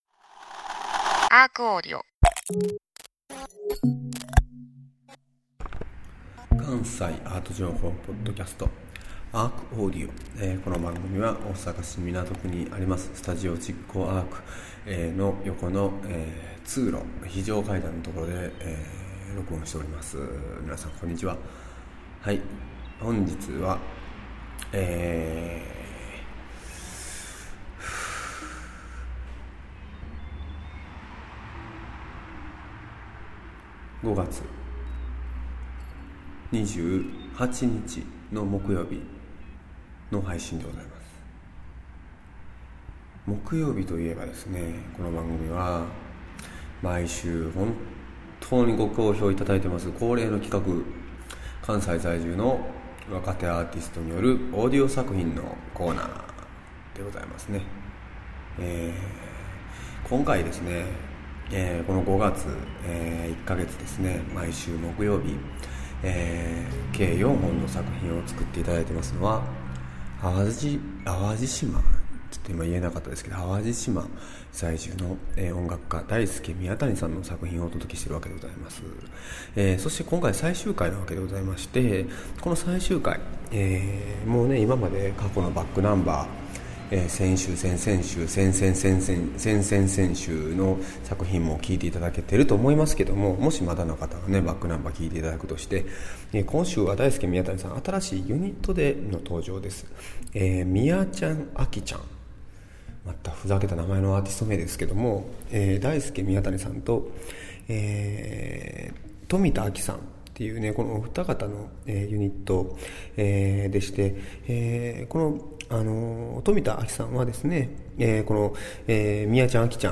その内容はアコースティックギターを主体とした穏やかな楽曲からCDスキップ、スピーカーのハウリングを用いたドローンと様々である。